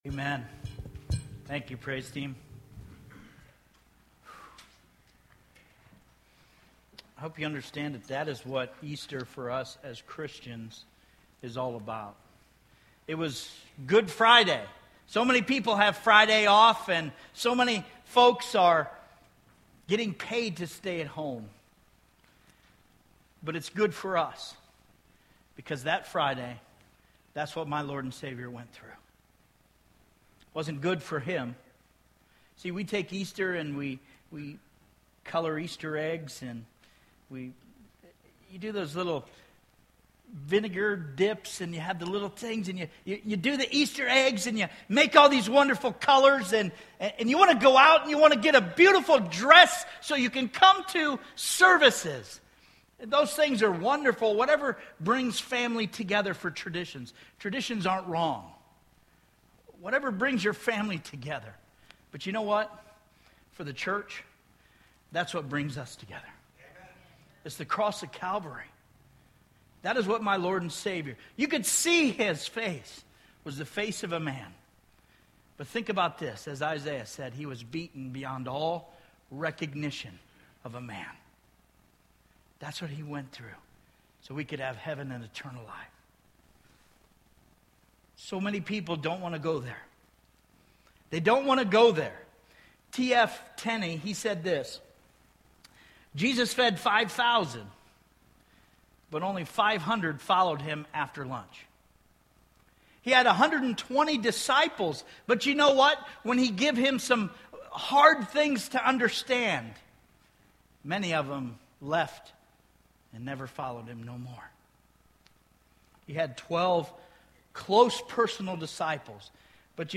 Sermons Archive - Page 31 of 52 -